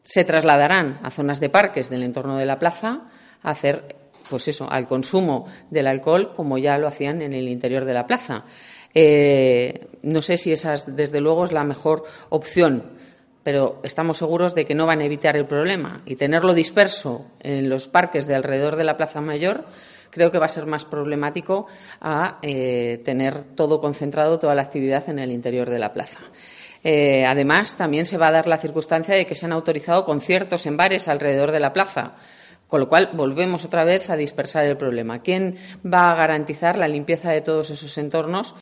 Clara Martín, portavoz socialista en el Ayuntamiento de Segovia, sobre la Tardebuena